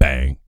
BS BANG 01-L.wav